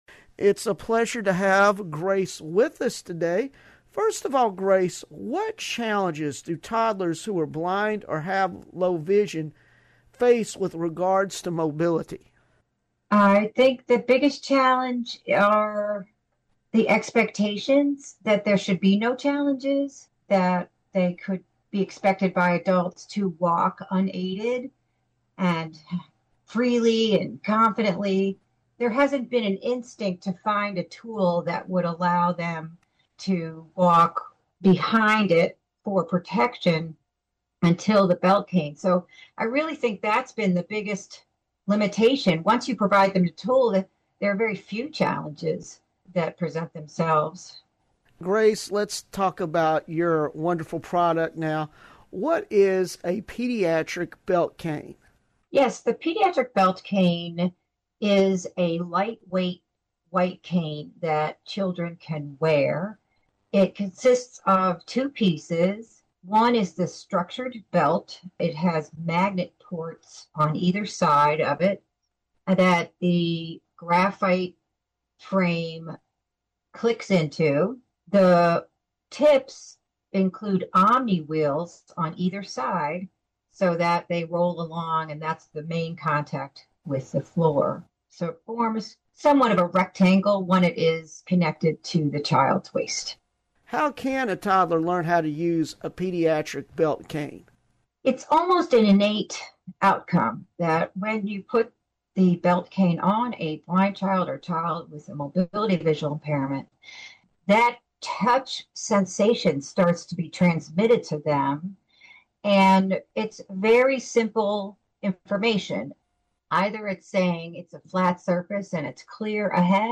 About the Interview and Why It Matters The interview will focus on the Pediatric Belt Cane—how it was developed, why early mobility is critical for children who are blind, and how this tool supports safe, confident exploration during the most formative years of development.